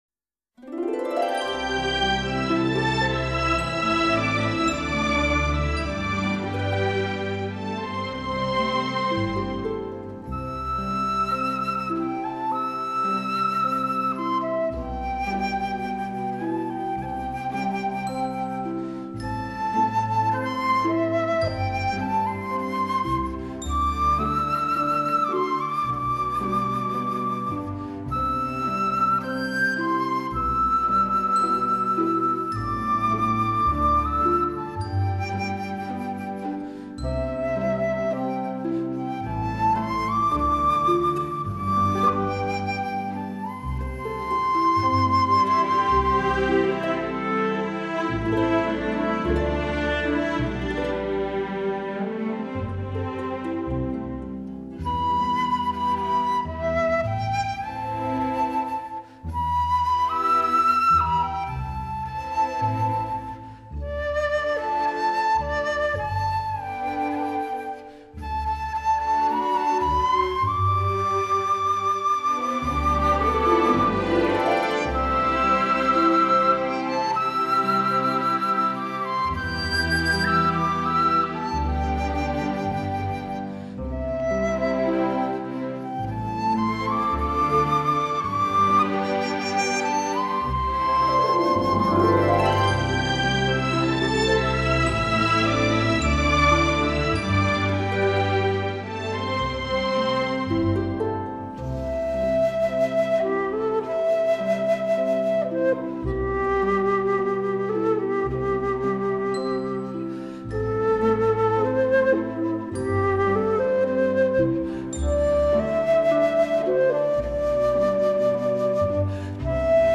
音乐类型：New  age
横跨古典与流行，结合传统与现代，融汇东方与西方音乐美学的跨界巨作。
所有乐器的比例定位、透明度、平衡、层次、音场、空间感，都好到令人赞不绝口。